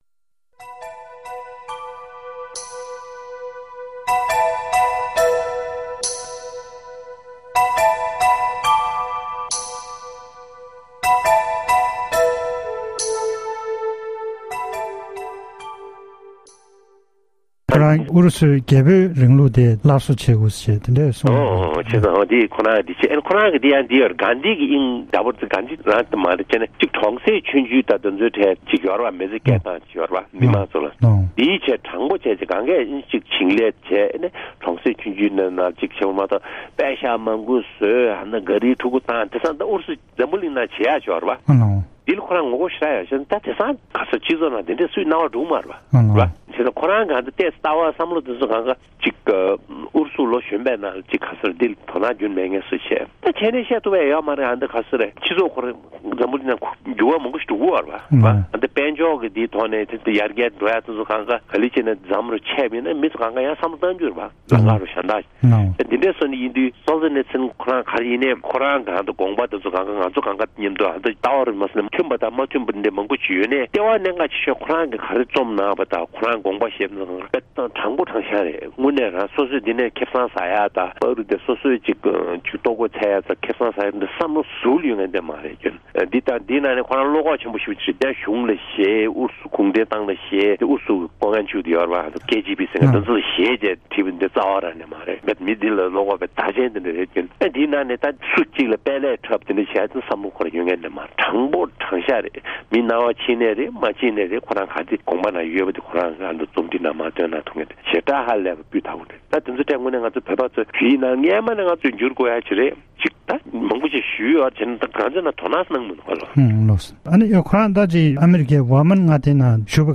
གནས་འདྲི་ཞུས་པའི་དུམ་མཚམས་གསུམ་པར་གསན་རོགས༎